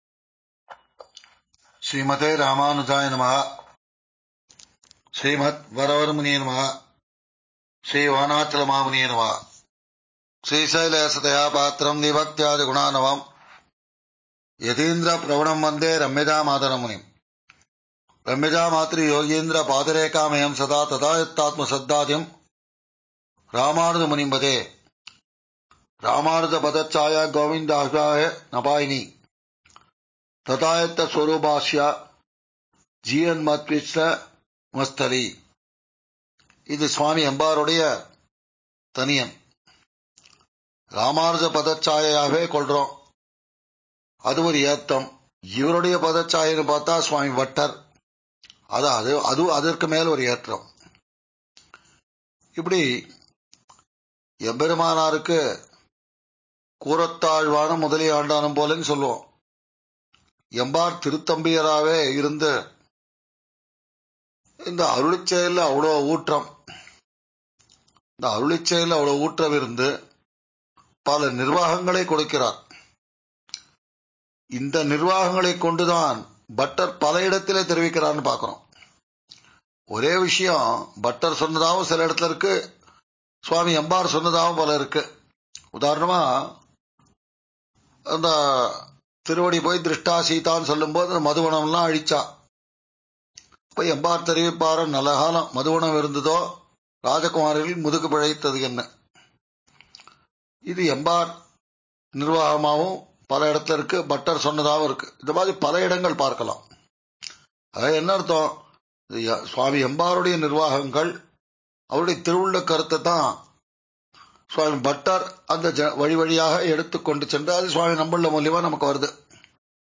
விஶ்வாவஸூ ௵ தை ௴ எம்பார் ஆயிரமாவது திருநக்ஷத்திர மஹோத்ஸவ உபன்யாஸ ஸமர்ப்பணம்.